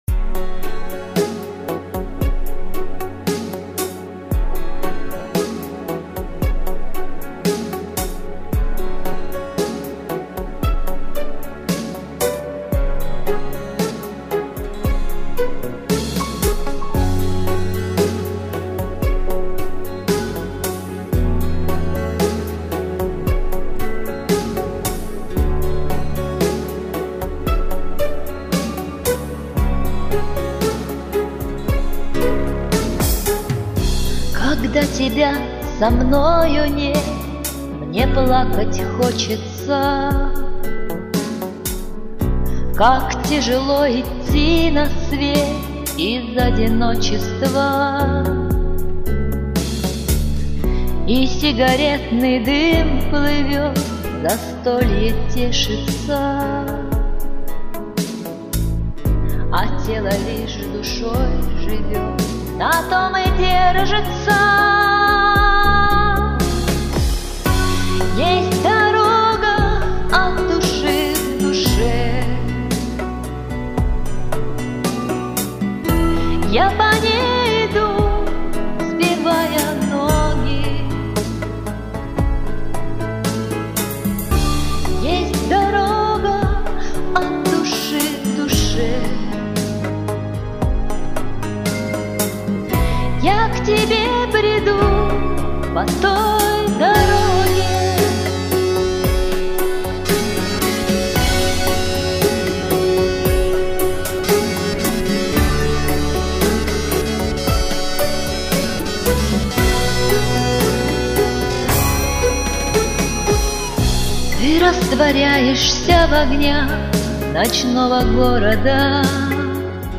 Чистенько!